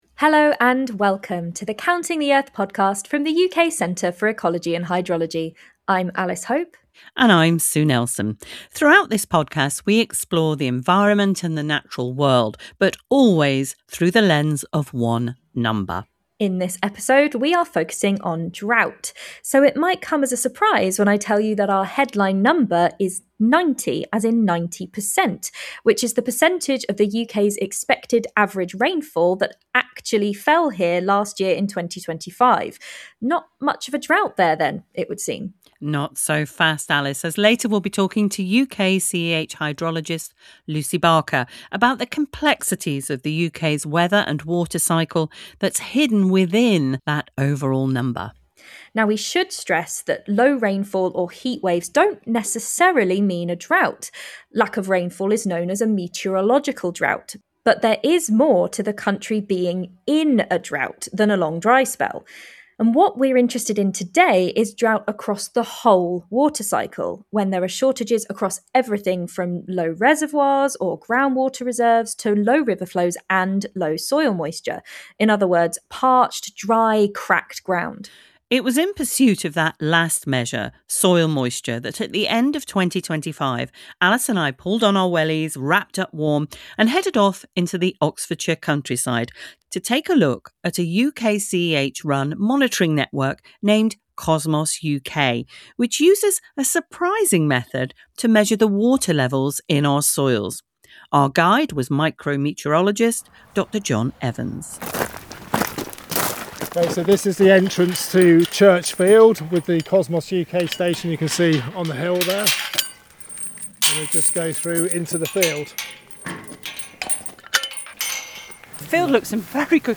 at a COSMOS-UK field site at Chimney Meadows Nature Reserve in Oxfordshire